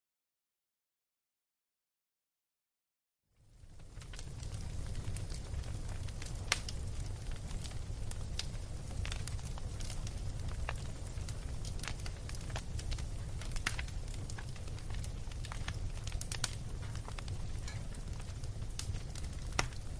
Fire Crackling
Fire Crackling is a free ambient sound effect available for download in MP3 format.
004_fire_crackling.mp3